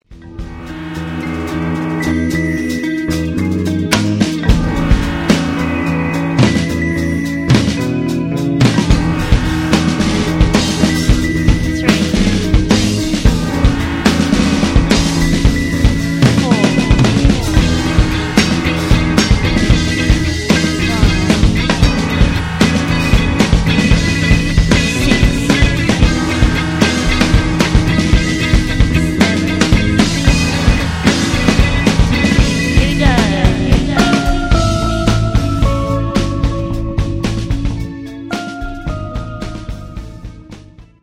321k MP3 (41 secs, mono)